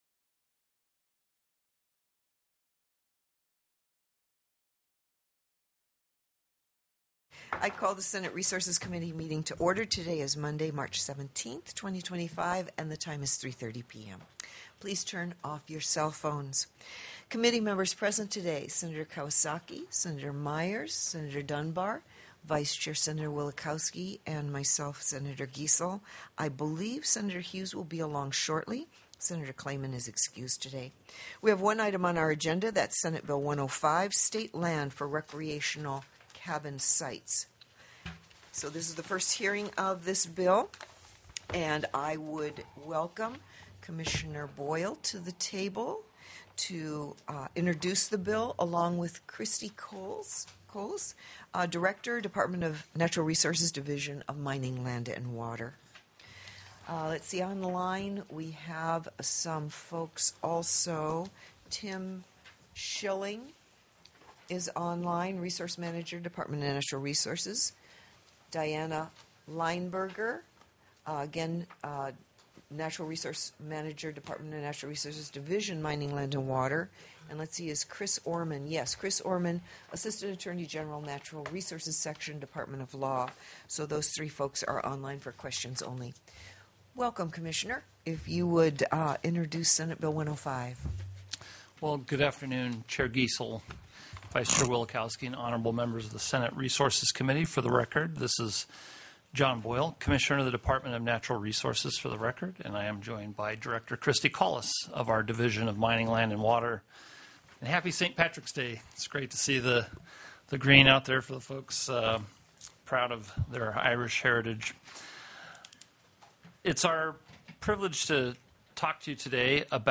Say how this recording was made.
The audio recordings are captured by our records offices as the official record of the meeting and will have more accurate timestamps. SB 105 STATE LAND FOR RECREATIONAL CABIN SITES TELECONFERENCED